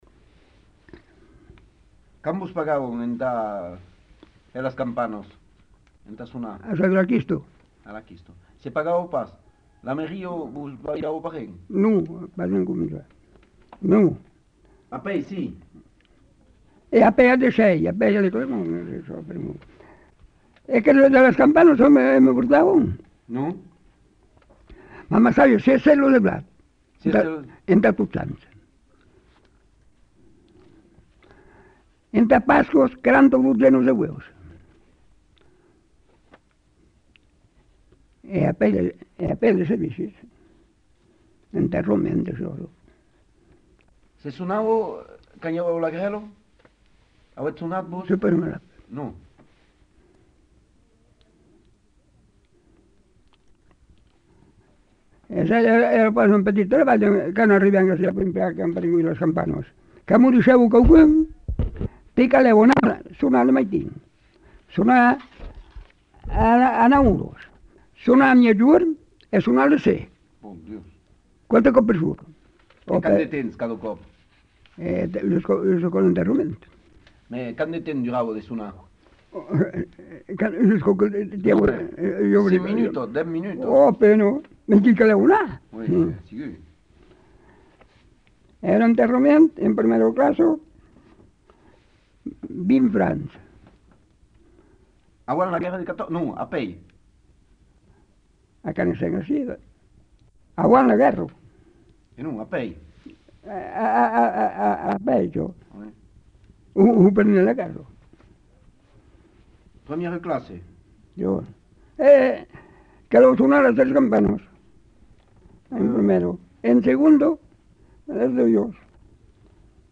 Lieu : Pompiac
Genre : récit de vie